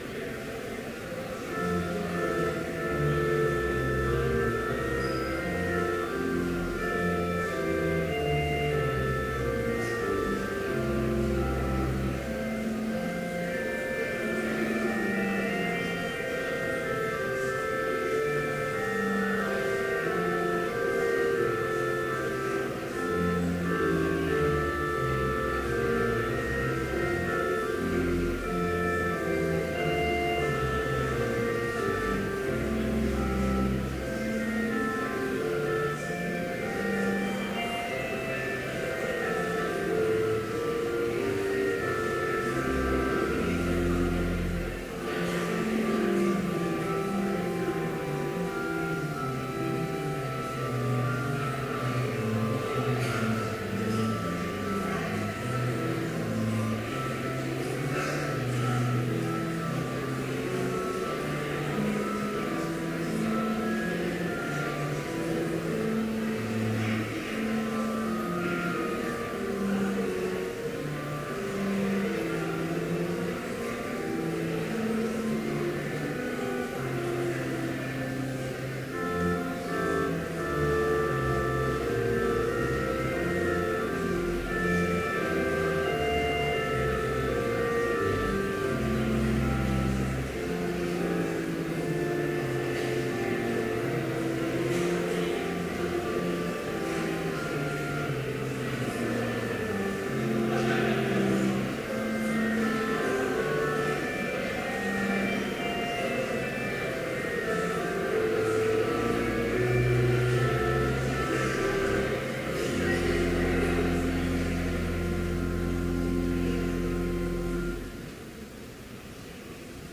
Complete service audio for Chapel - September 24, 2014